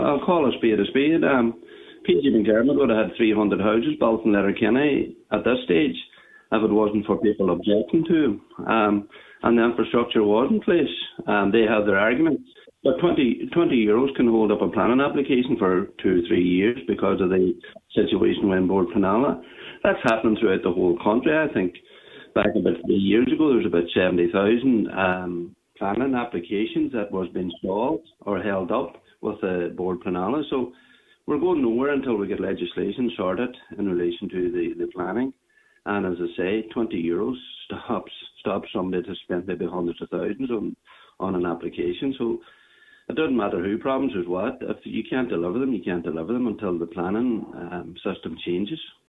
Cllr Canning told the Nine til Noon Show that as it stands, it is too easy to delay planning applications: